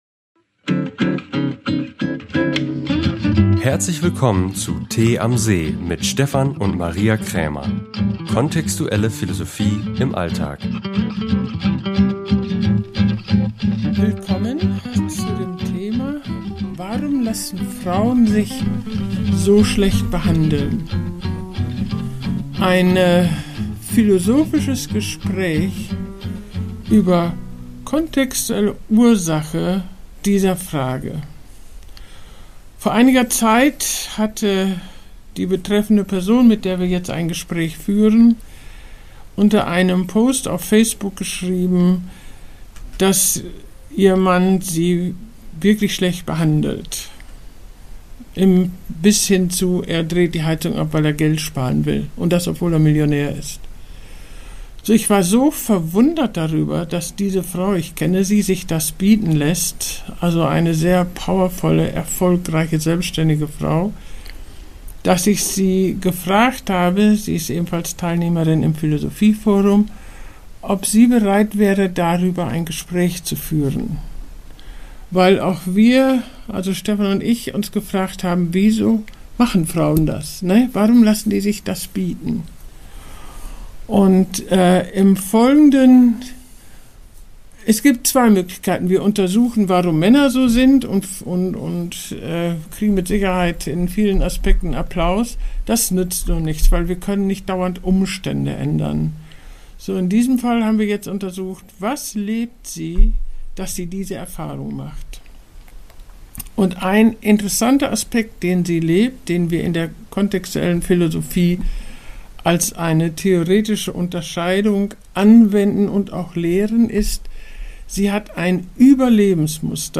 Ein philosophisches Gespräch über contextuelle Ursachen.